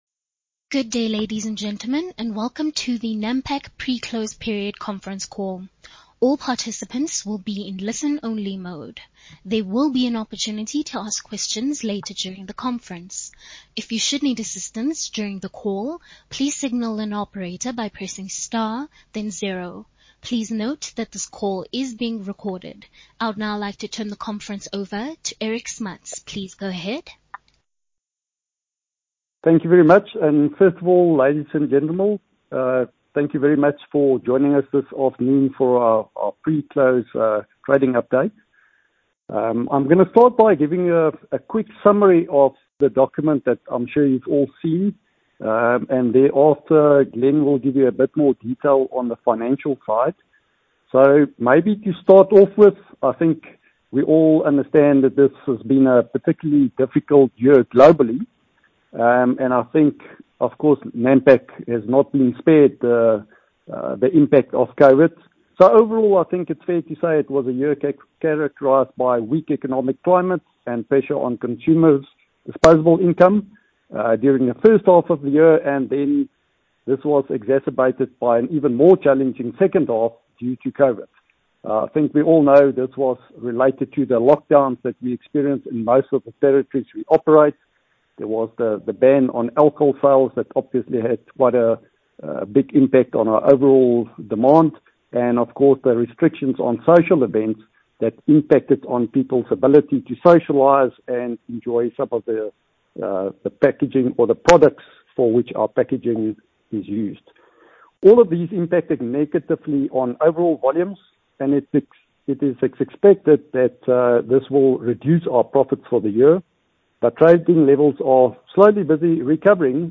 Presentations and market updates September 2020 Pre-close Conference Call (Audio) 2020 Year-end Results Presentation Webcast 2020 Interim Results Presentation Webcast March 2020 Pre-close Conference Call (Audio)